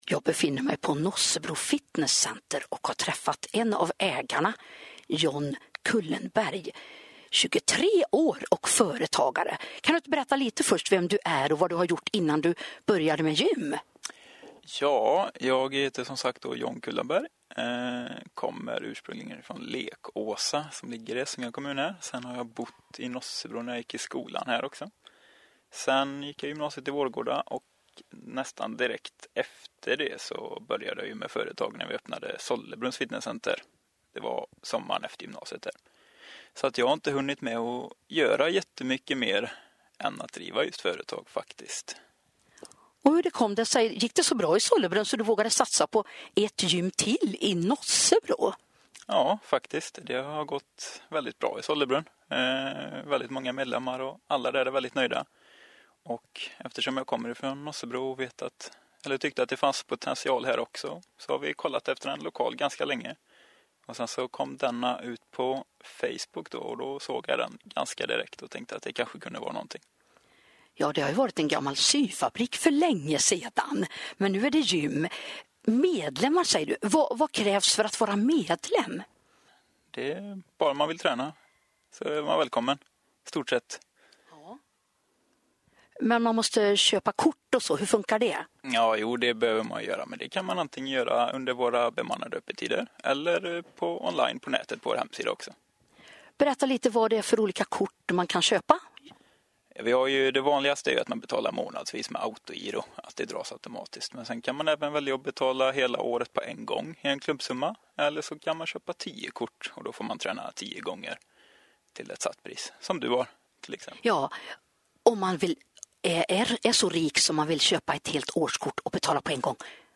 Två foton på oss, sitter i en soffa under intervjun